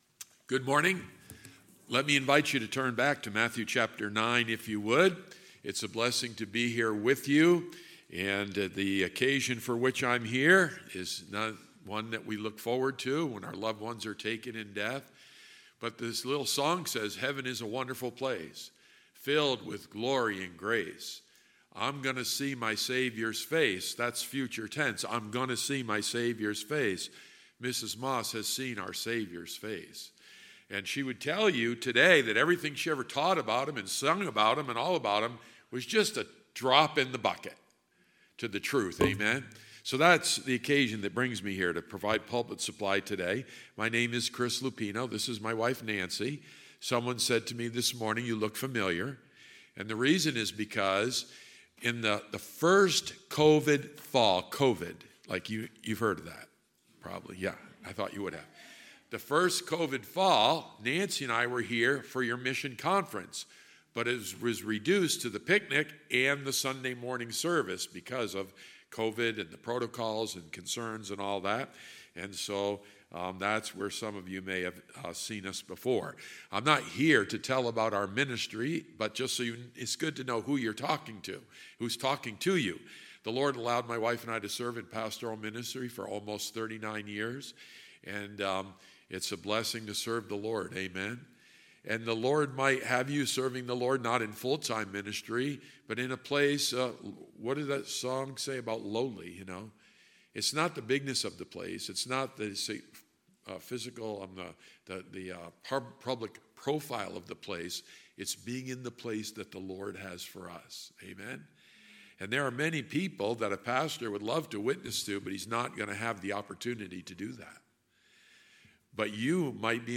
Sunday, September 24, 2023 – Sunday AM